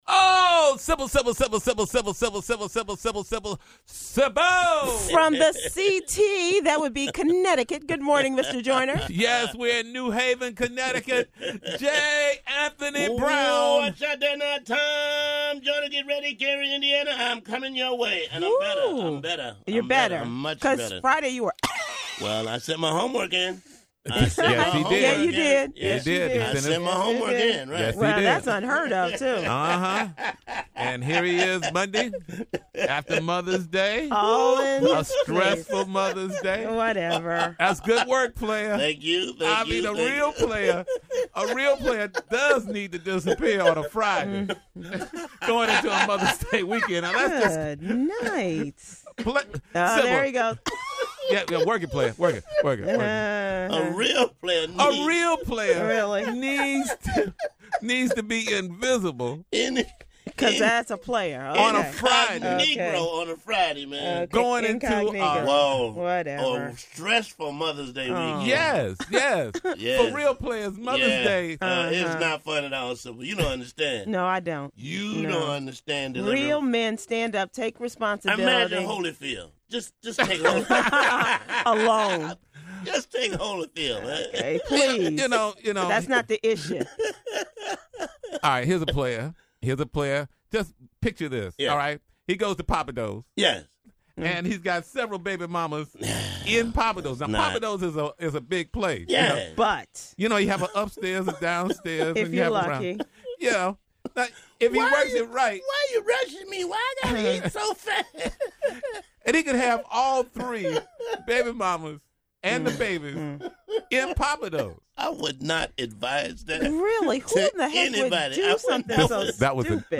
The TJMS crew is live from New Haven, Connecticut and is out to meet every listener! Click the link above to hear who Tom was expecting a shout out from and why Houston has a problem!